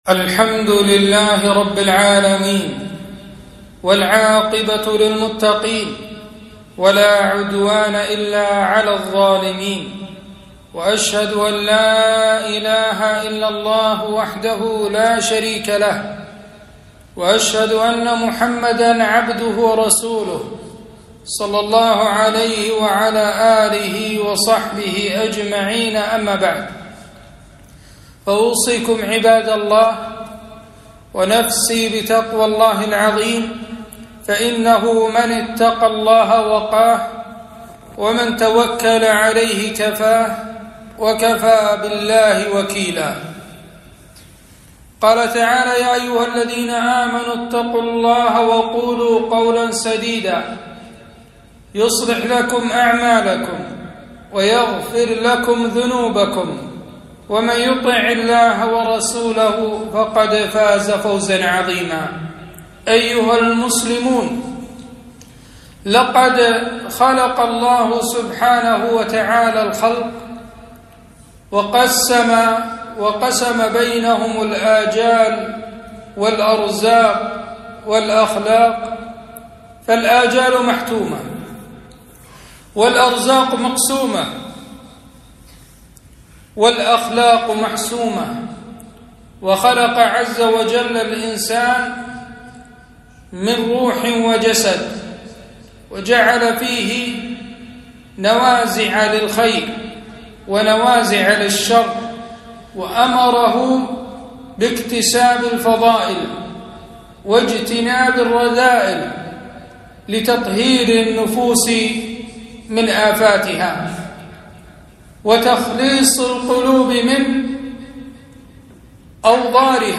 خطبة - لا يزال الناس بخير ما لم يتحاسدوا